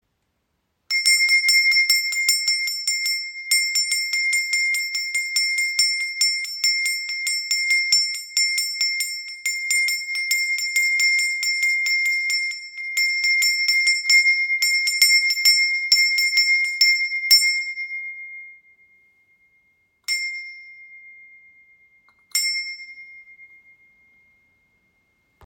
Indische Tempelglocke – Traditionelles Ritualinstrument
Diese indische Tempelglocke aus Messing erzeugt einen klaren, reinen Klang, der ideal für Meditation und Rituale ist.
• Material: Messing
• Höhe: 8,5 cm, ø 9 cm